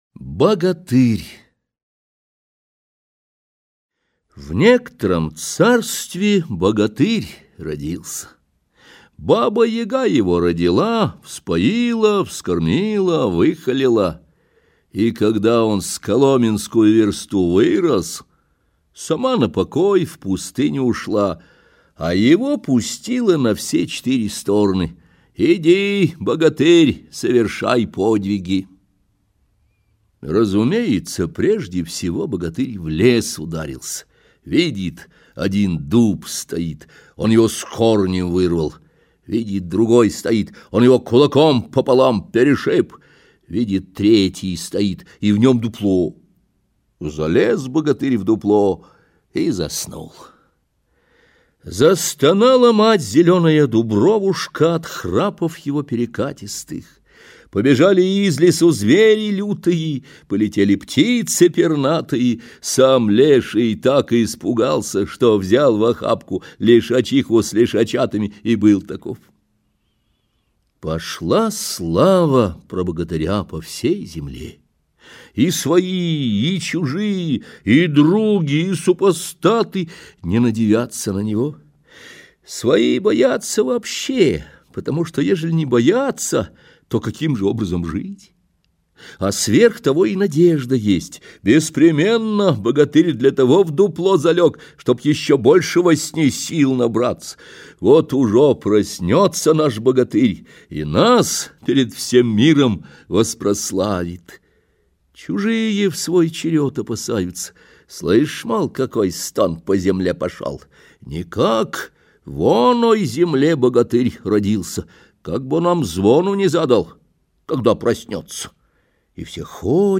Богатырь - аудиосказка Салтыкова-Щедрина М.Е. В этой сказке писатель в иносказательной форме сравнивает Богатыря с русским самодержавием.